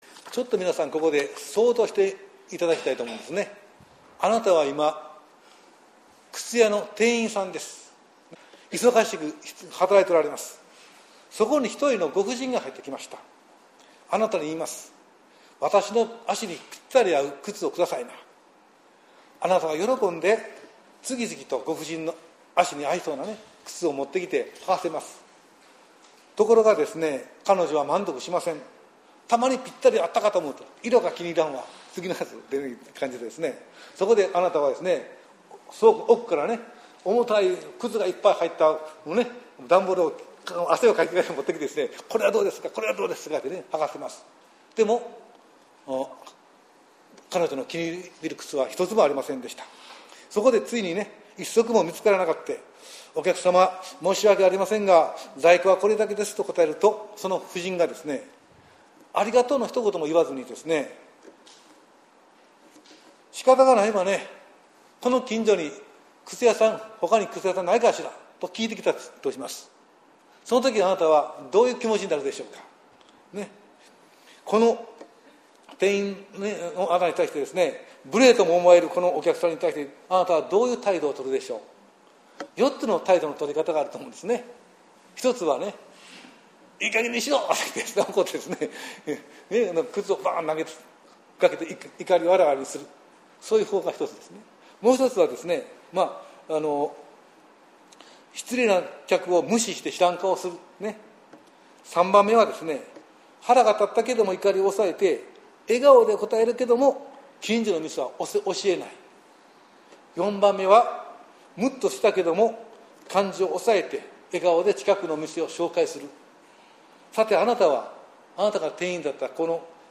説教集 | あお福音ルーテル教会
あお福音ルーテル教会の礼拝説教を音声で公開しております。